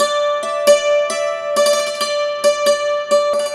Dulcimer13_135_G.wav